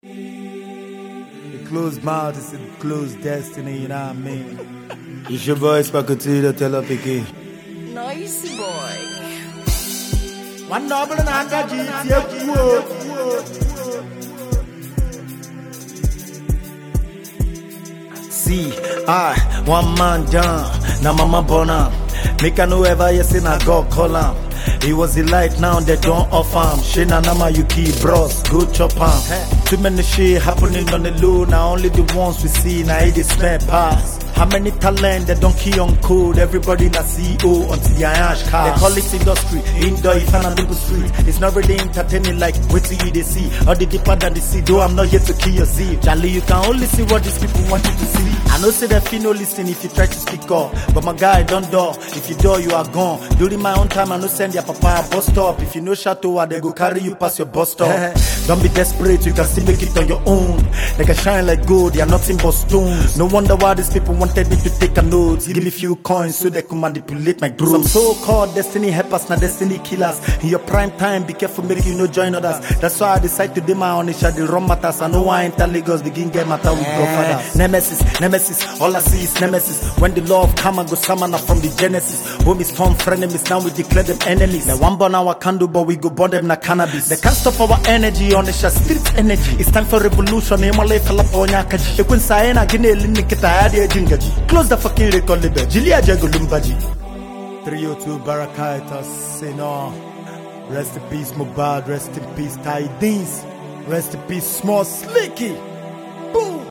Well renowned Nigerian rapper and performer
gbedu song